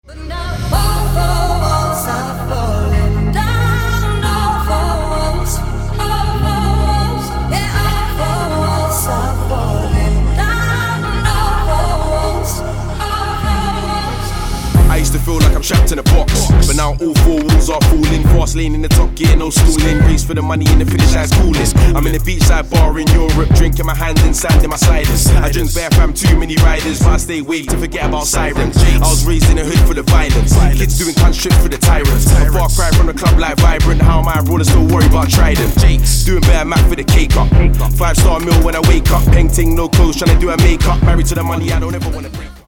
• Качество: 192, Stereo
Хип-хоп
Rap
Grime
Uk Grime